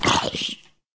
sounds / mob / zombie / hurt1.ogg
hurt1.ogg